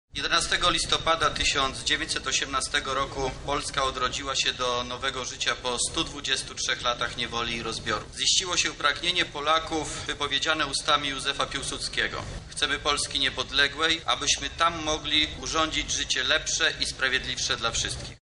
Oficjalne obchody na Placu Litewskim rozpoczęły się punktualnie o godzinie 11:45.
O niepodległości mówił wojewoda lubelski, Wojciech Wilk: